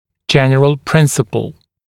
[‘ʤen(ə)r(ə)l ‘prɪnsəpl][‘джэн(э)р(э)л ‘принсэпл]общий принцип